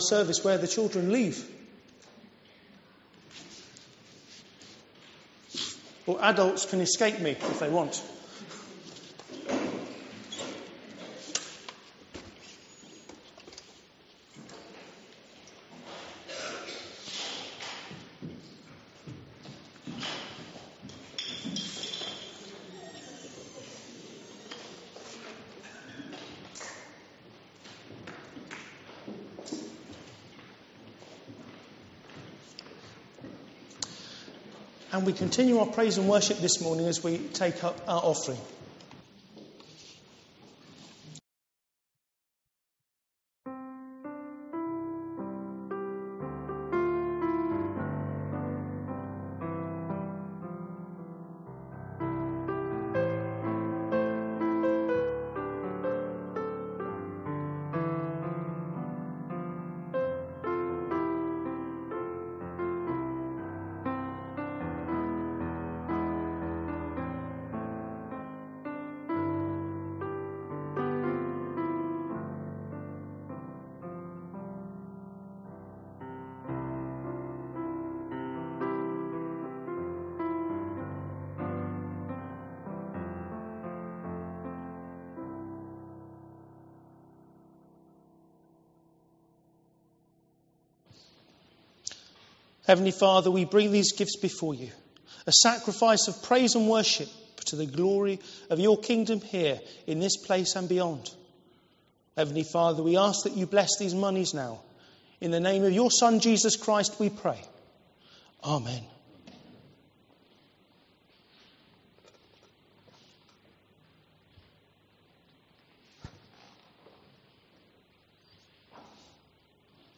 An audio file of the service is now available to listen to.